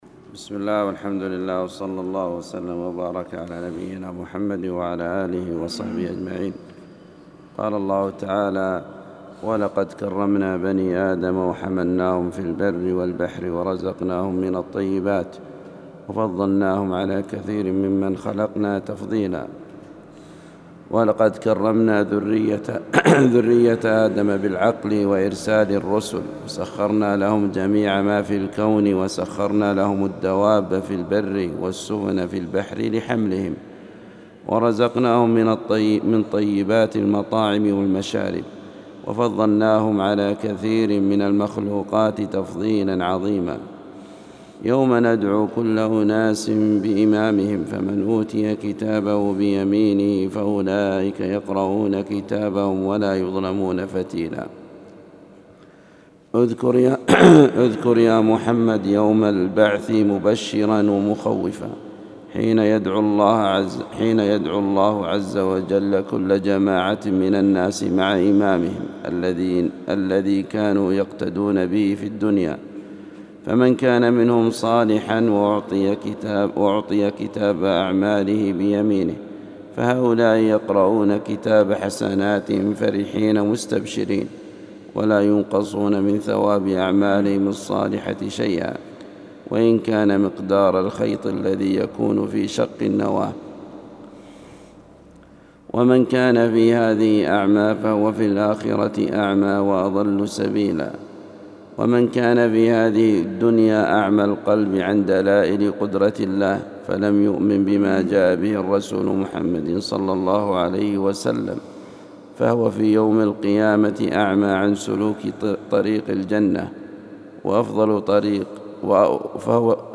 القرآن الكريم وعلومه     التفسير      سورة الإسراء